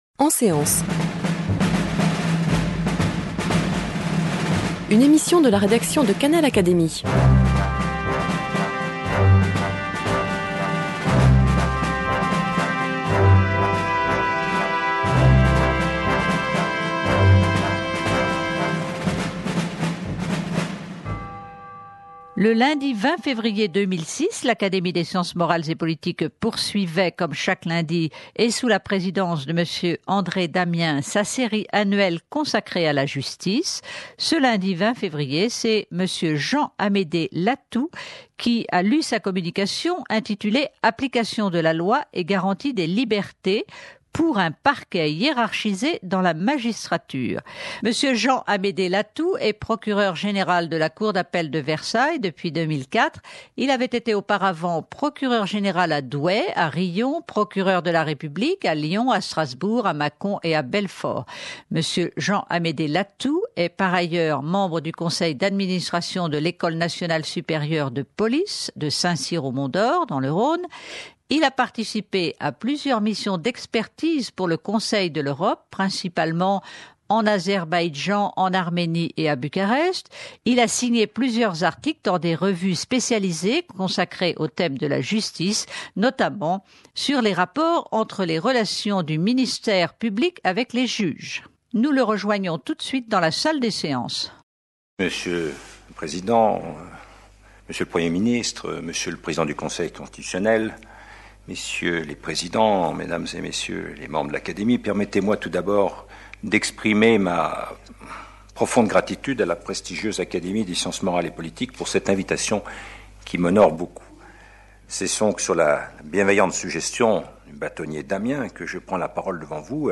Jean-Amédée Lathoud, procureur de la cour d’appel de Versailles, présente sa communication sur l’organisation judiciaire, devant l’Académie des sciences morales et politiques.
Il présente sa communication le 20 février 2006 en séance publique.